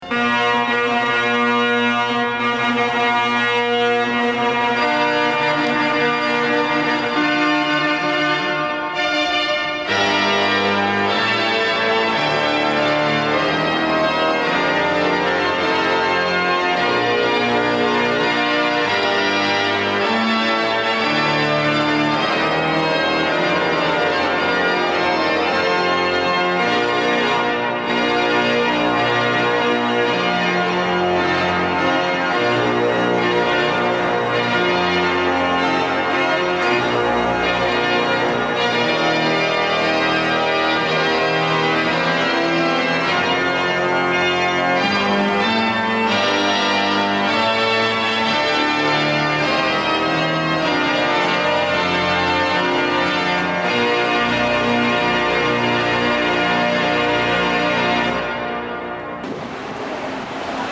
Notre répertoire  SOPRANO et CLAVIER :